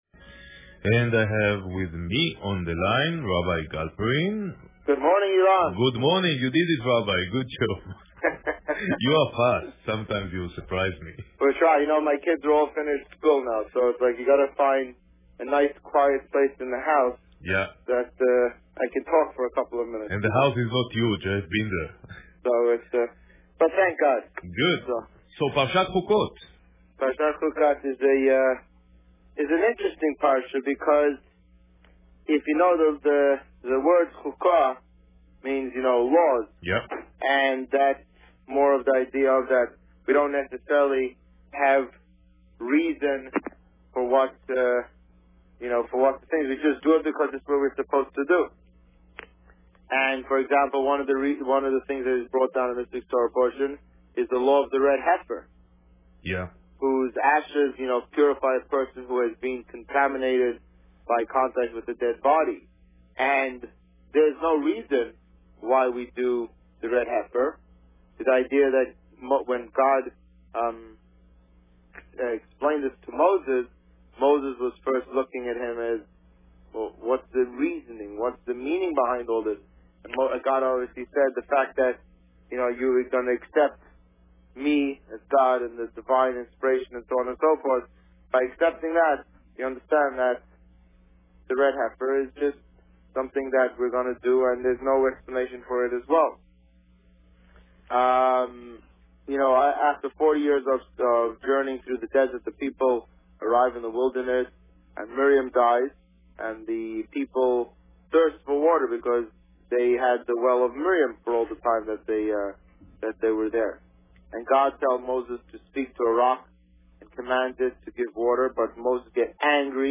You are here: Visitor Favourites The Rabbi on Radio The Rabbi on Radio Parsha Chukat Published: 13 June 2013 | Written by Administrator This week, the Rabbi spoke about Parsha Chukat. Listen to the interview here .